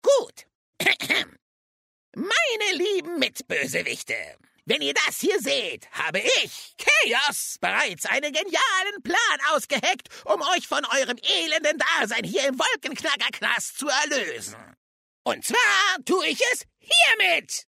"excellent actors"